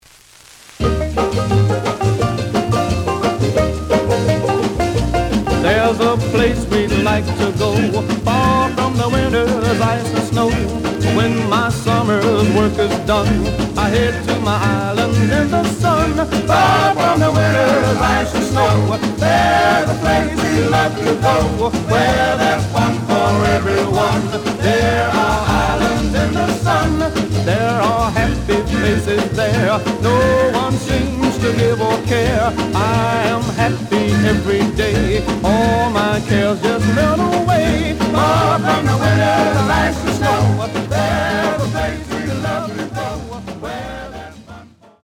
The audio sample is recorded from the actual item.
●Genre: Rhythm And Blues / Rock 'n' Roll
Slight noise on beginning of both sides, but almost good.)